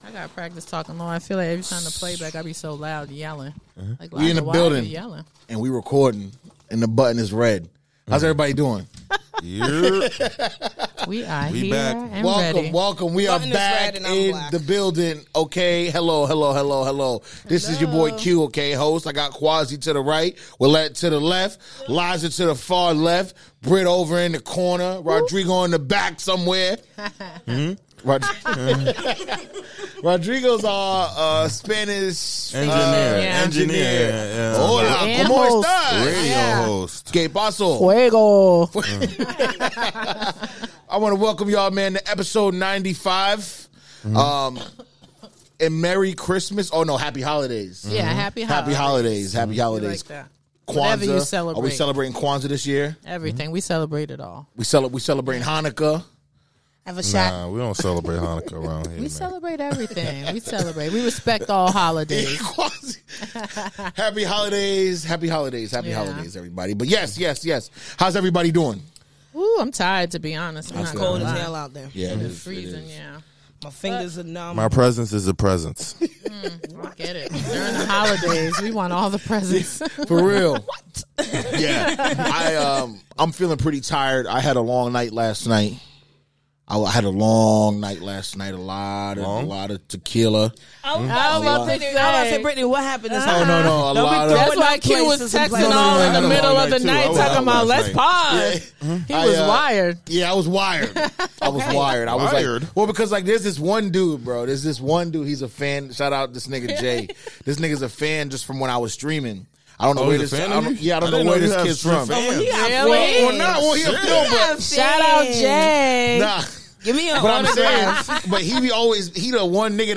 High energy, high speed, a lot of noise, a lot of fun.